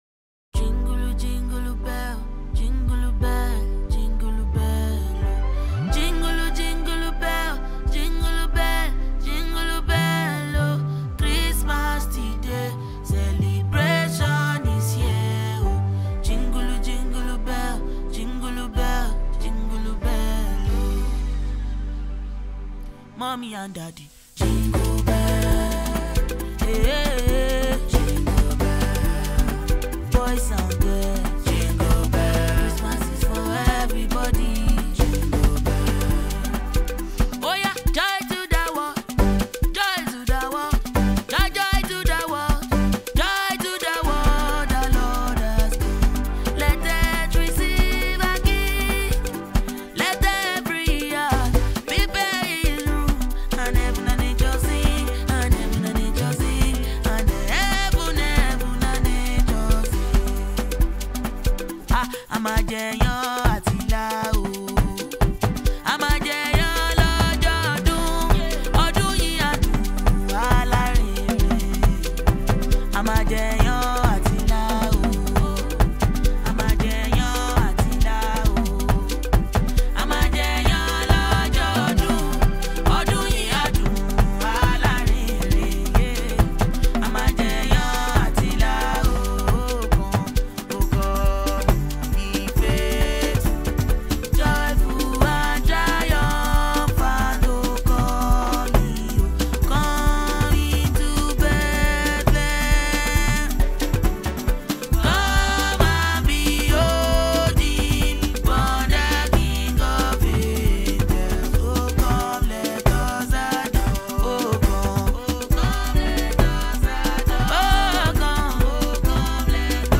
Prominent Nigerian female singer and performer
is a unique song with a breathtaking beat